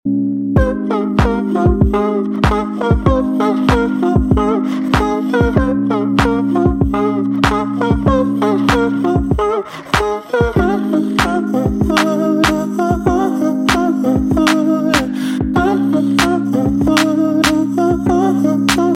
• Качество: 128, Stereo
спокойные
без слов
RnB